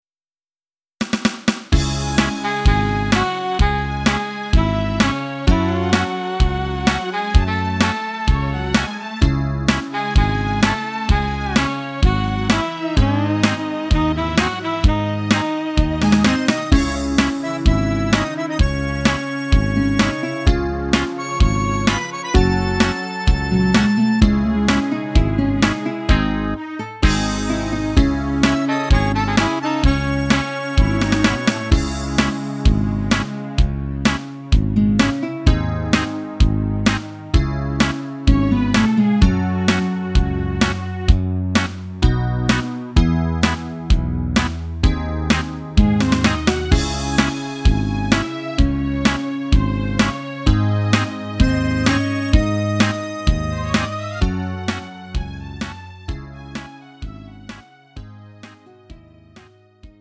음정 원키 3:48
장르 가요 구분 Lite MR
Lite MR은 저렴한 가격에 간단한 연습이나 취미용으로 활용할 수 있는 가벼운 반주입니다.